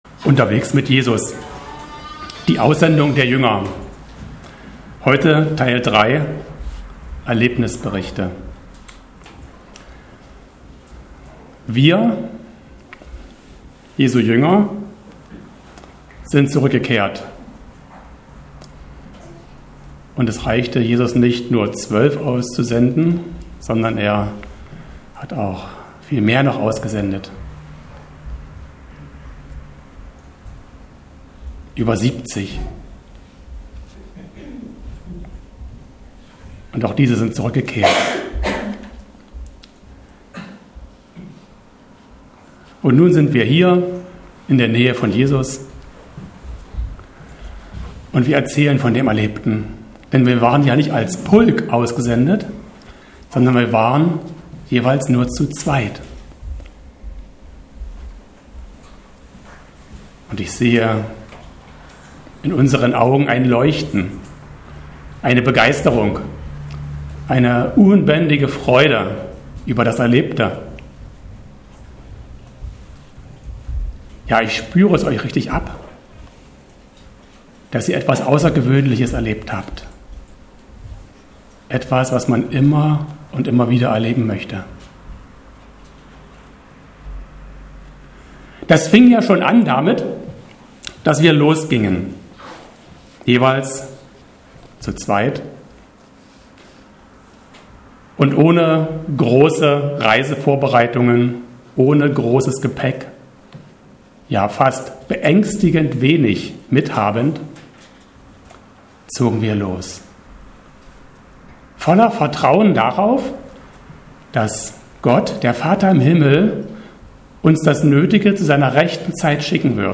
Predigten Falls kein Player angezeigt wird, unterstützt Ihr Browser kein HTML5.